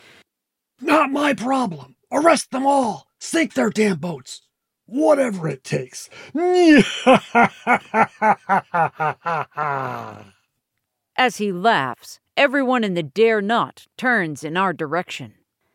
For example: “He laughs, a deep, loud baritone laugh, like a maniacal melodrama villain.”
Because we were recording the book, we decided it made more sense to deliver the laugh instead of describing it.
Fenton-Feltons-laugh.m4a